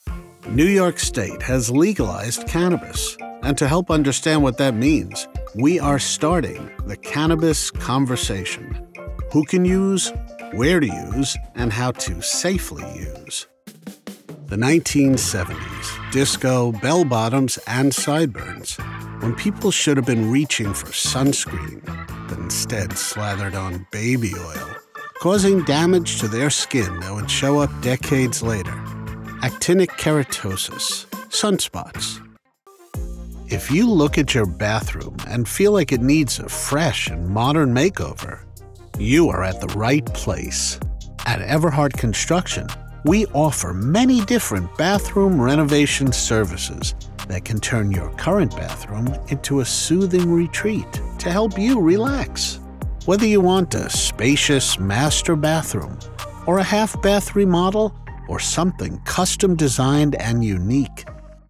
eLearning / Technical Narration